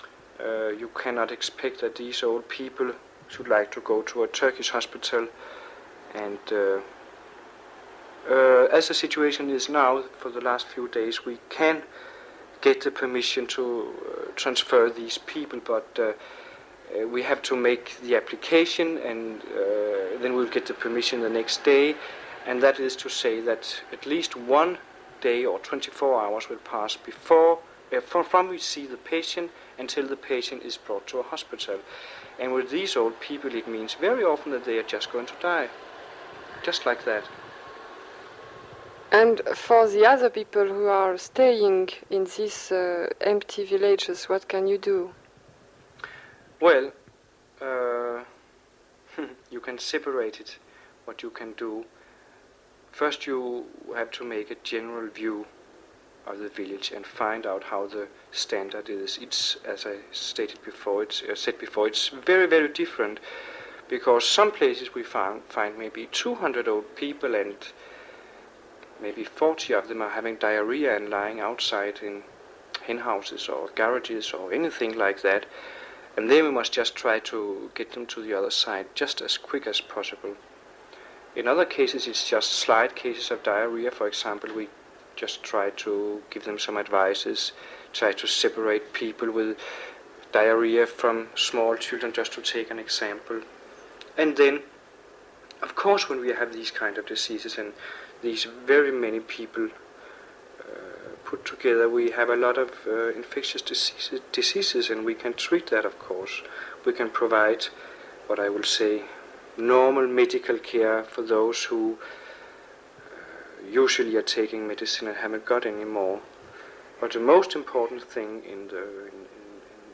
The results of their study are presented in the exhibition, where visitors also have the opportunity to participate in the experience by listening to testimonies recorded in wartime contexts and through the emotions they evoke.
Excerpt from the testimony of a Danish Red Cross doctor on mission for the ICRC in Cyprus. 1974.